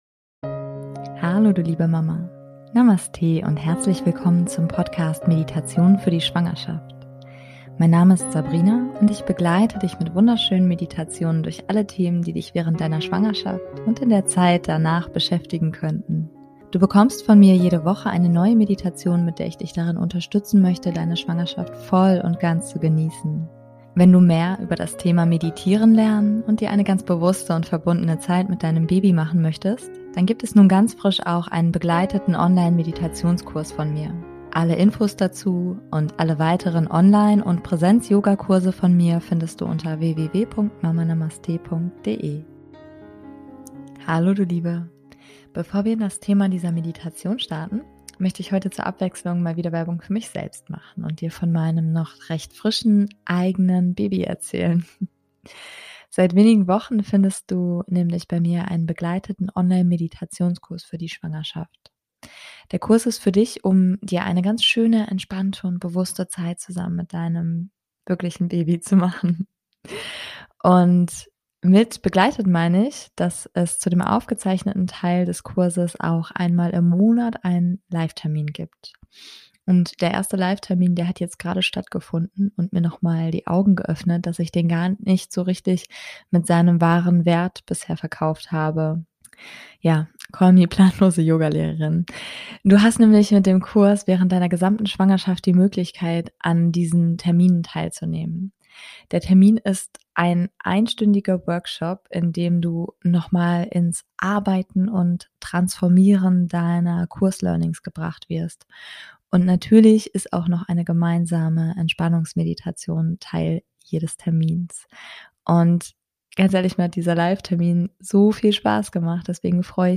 Deswegen gibt es diese Meditation heute für dich.